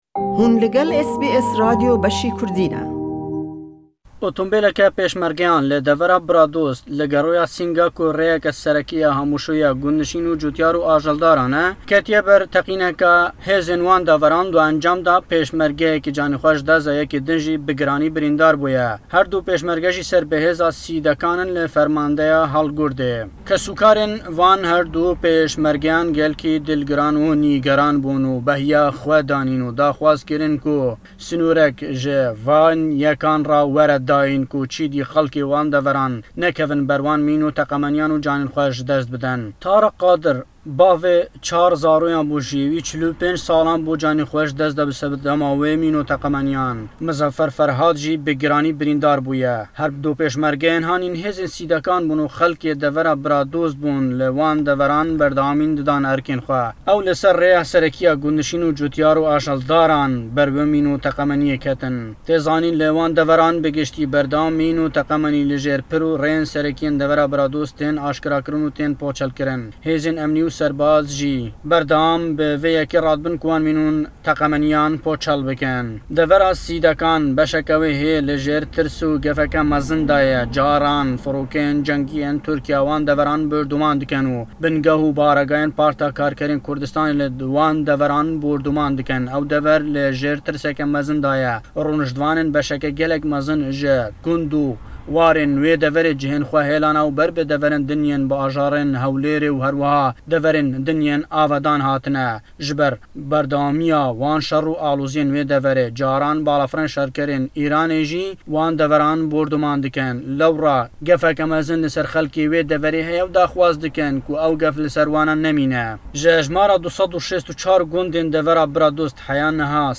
Le em raporte da ke Hewlêre we: çekdarî Daiş berdewamin le çalakî û jîyanî xellkanî sîvîl û hezî Pêşmerg dexene metirsî ye we le Herêmî Kurdistan. Le naçey Sîdekan kêşey mîn çareser nekrawe.